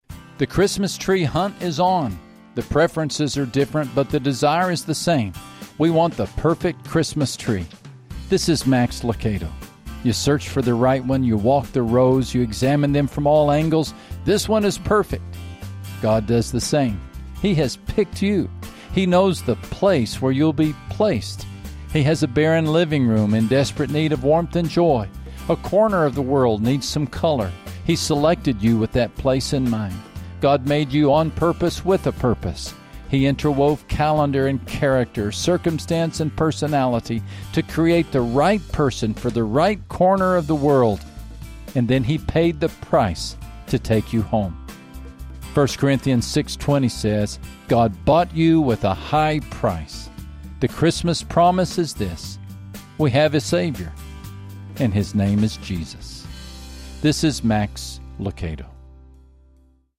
Max shares words of hope and help through simple, one-minute daily devotionals to encourage you to take one step closer to Jesus.